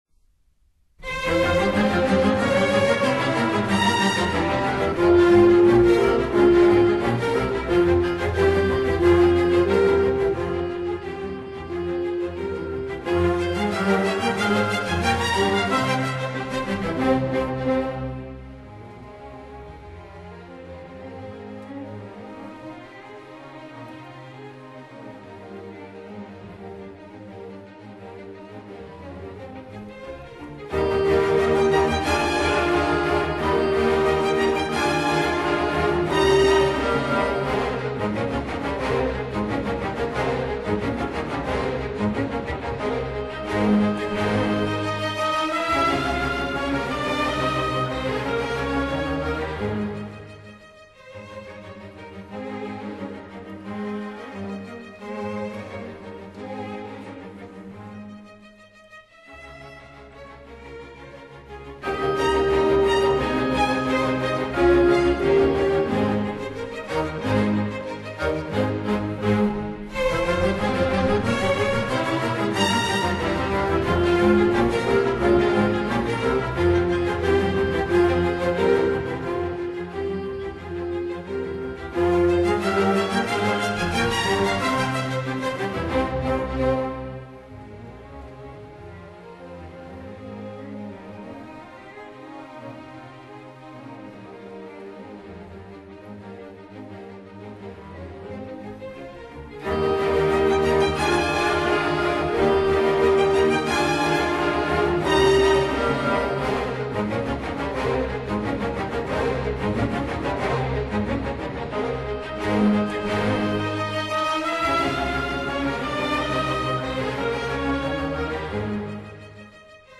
In F Minor